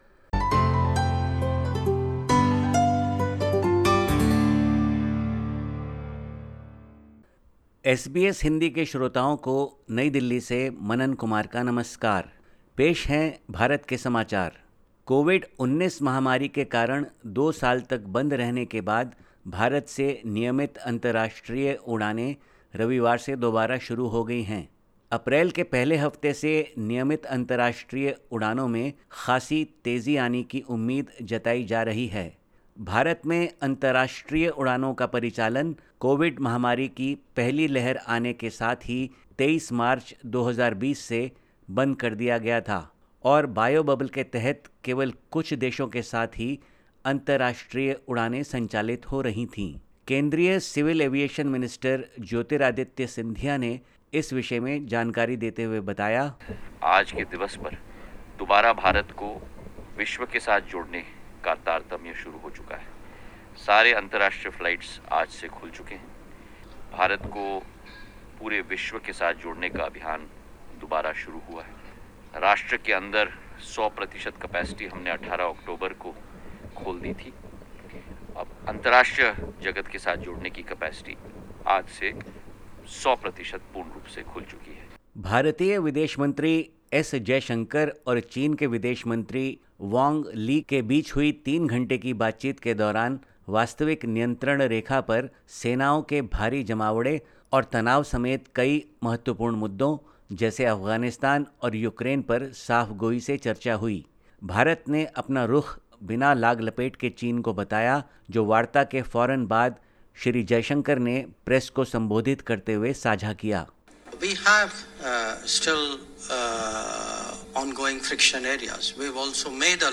Listen to the latest SBS Hindi report from India. 28/03/2022